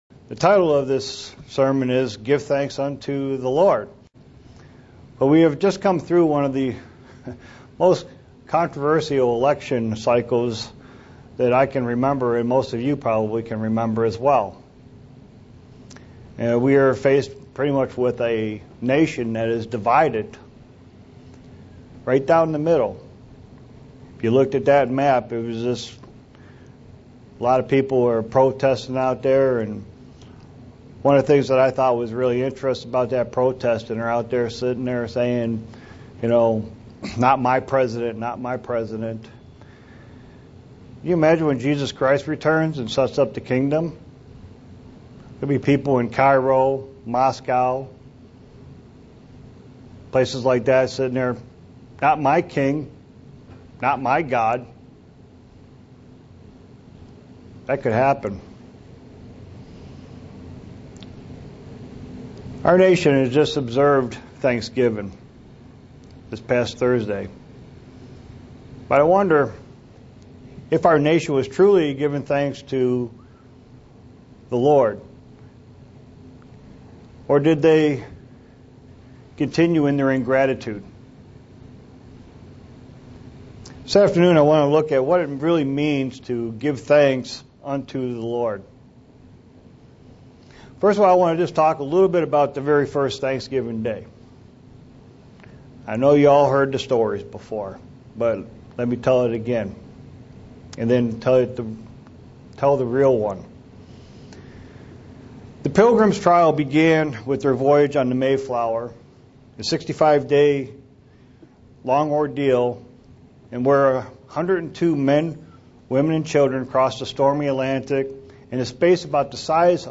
Given in Buffalo, NY
Print What it means to give thanks unto the Lord SEE VIDEO BELOW UCG Sermon Studying the bible?